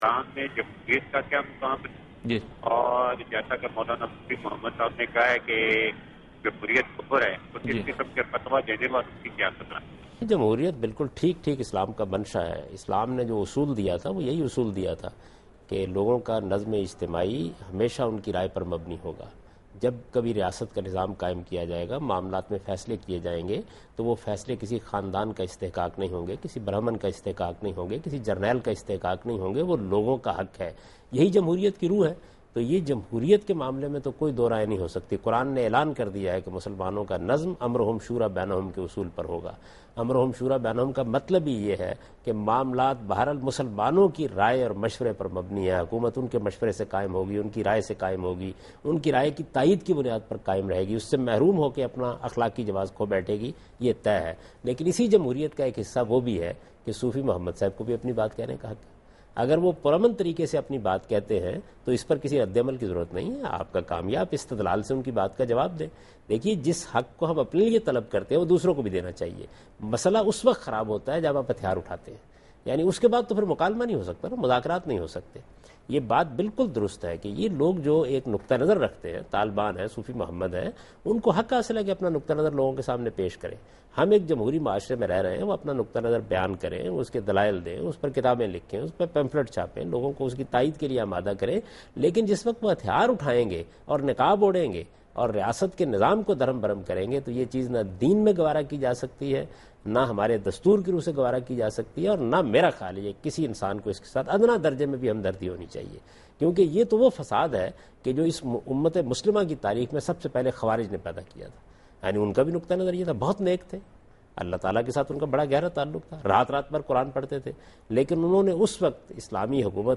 Category: TV Programs / Dunya News / Deen-o-Daanish / Questions_Answers /